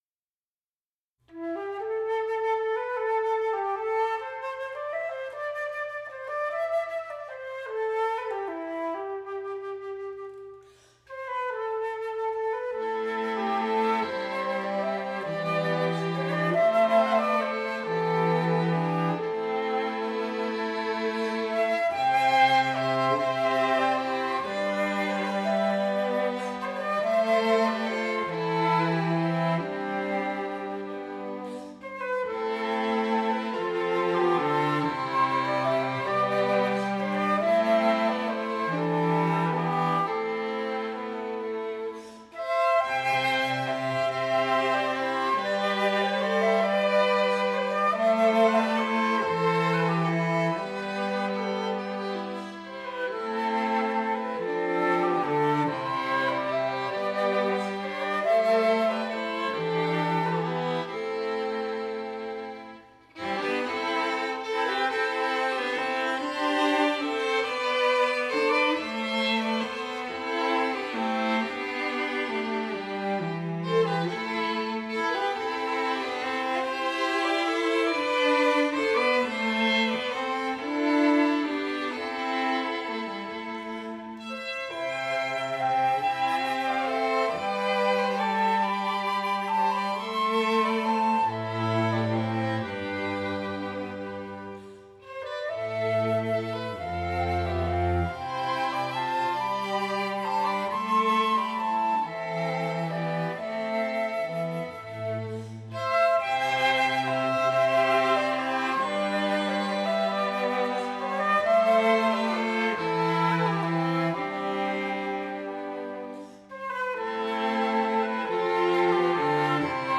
Кельтская